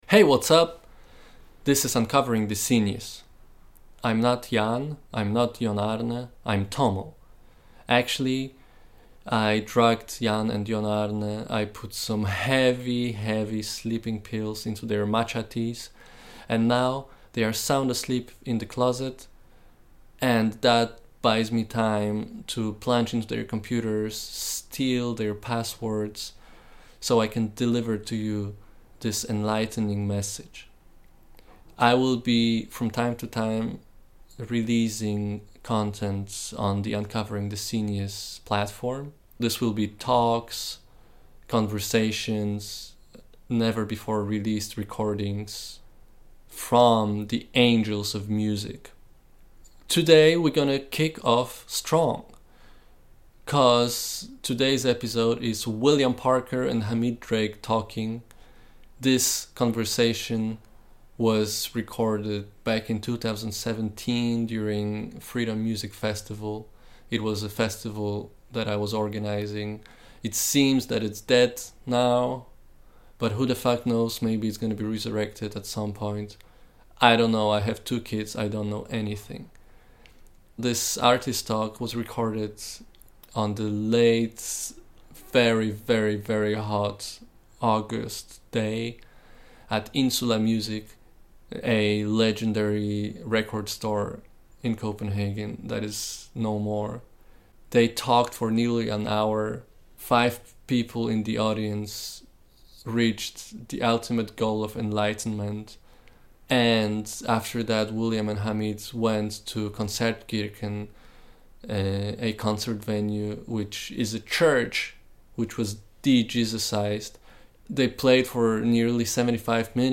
William Parker & Hamid Drake are two true legends from the Lower East Side, NYC & Chicago scenes respectively. This is a recording from the artist talk they gave during Freedom Music Festival vol. 3 in Copenhagen in 2017.